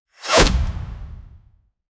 A sharp fantasy bow string release with a quick, crisp arrow shot and a subtle fiery whoosh layer, short and punchy.
a-sharp-fantasy-bow-strin-r6mnfkss.wav